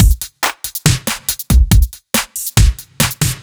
Index of /musicradar/french-house-chillout-samples/140bpm/Beats
FHC_BeatA_140-02.wav